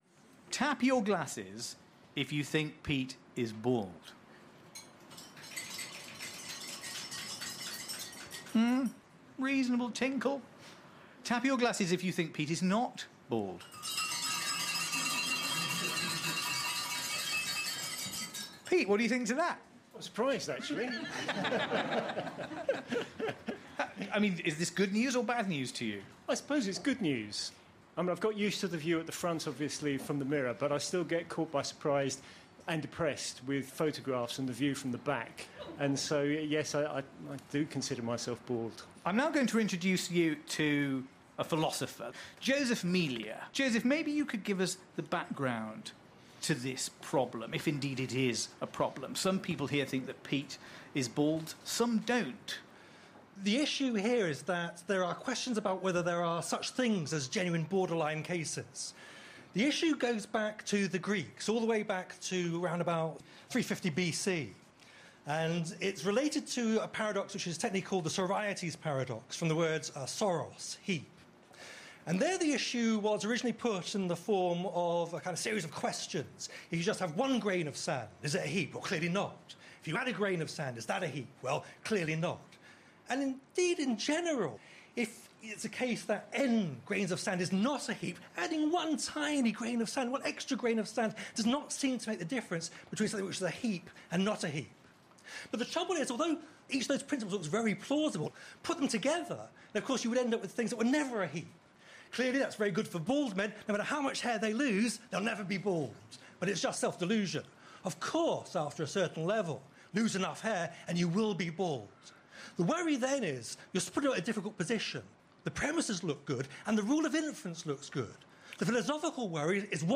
Speaking to presenter Matthew Sweet